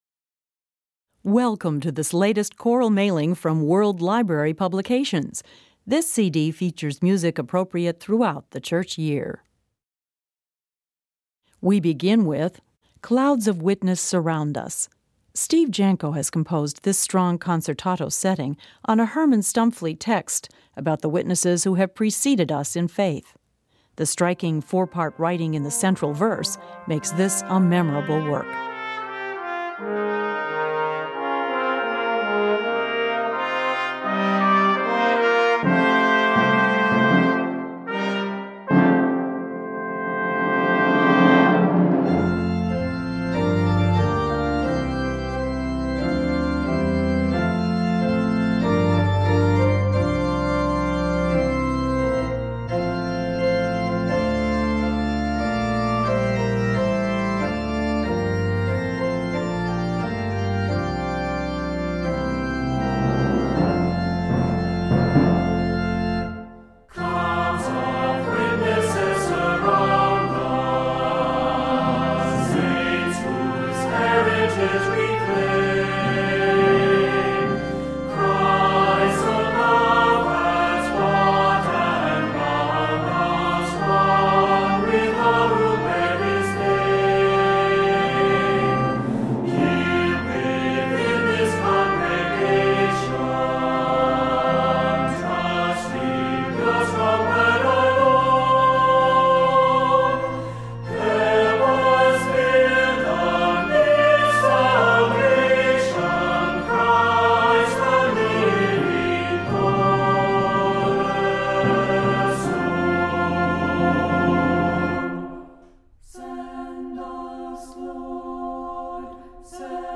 Voicing: Assembly, descant,SATB,Soprano Soloist